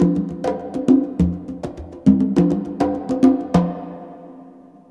CONGBEAT13-L.wav